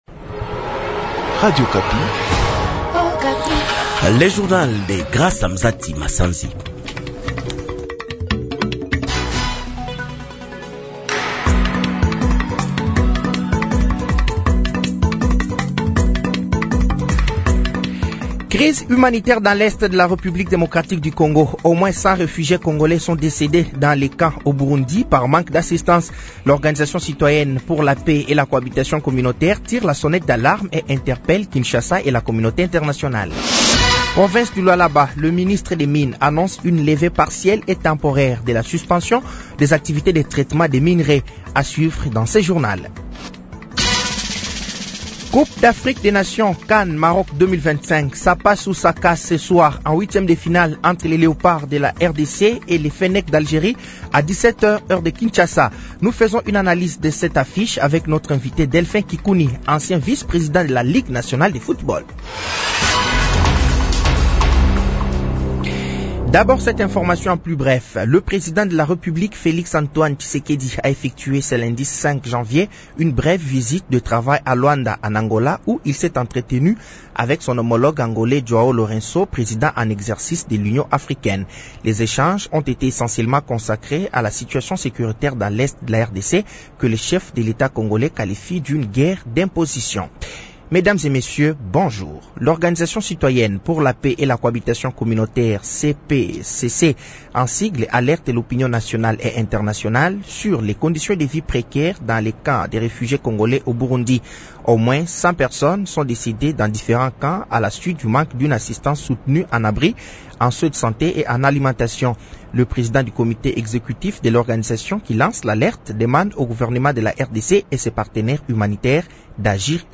Journal français de 07h de ce mardi 06 janvier 2026